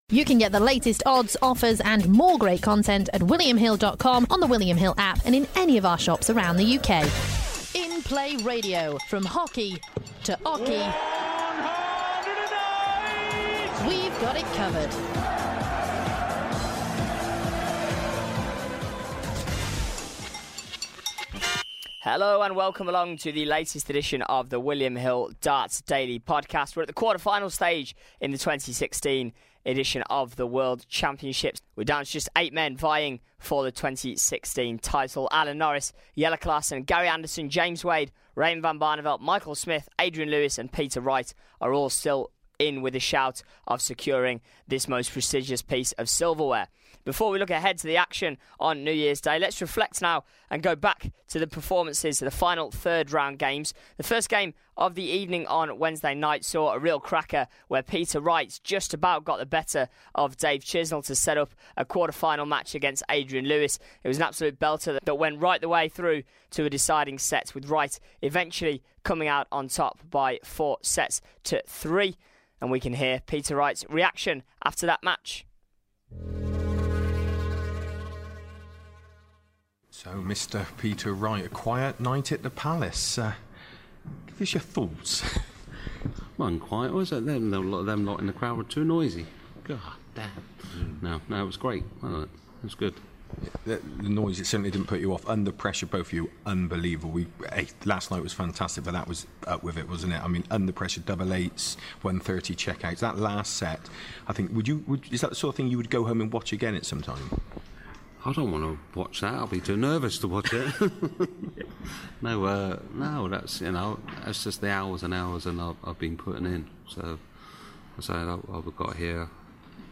On this edition we hear from the winners on the night: Peter Wright, Alan Norris and Jelle Klaasen before Rod Harrington previews the best of tonight's action.